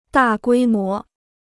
大规模 (dà guī mó) Free Chinese Dictionary